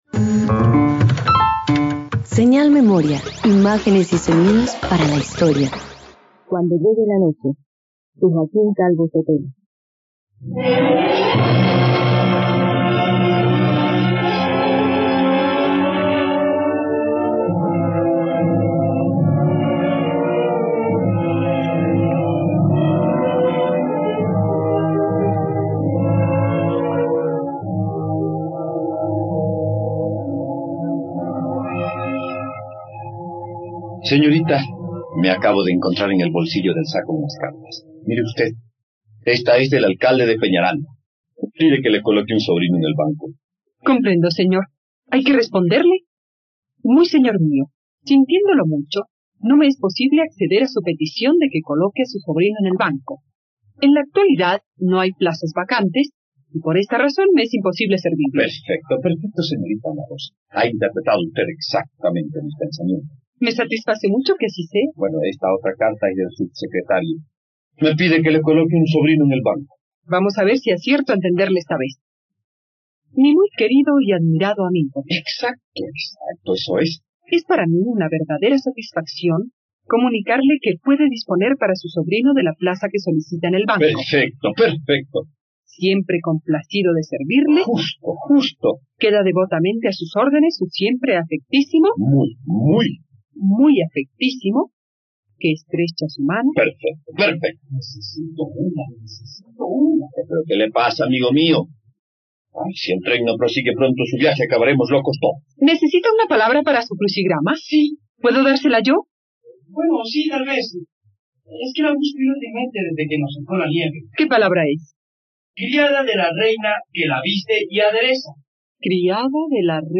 ..Radioteatro. Escucha la historia de Guillermo y Ana Rosa en ‘Cuando llegue la noche’.